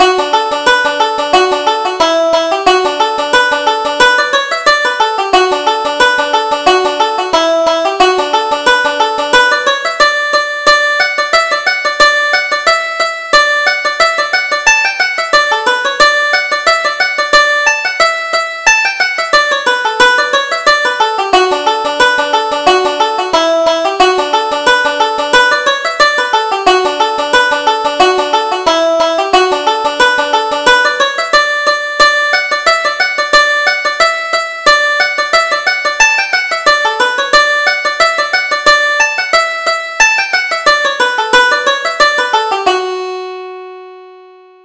Reel: Leather Buttons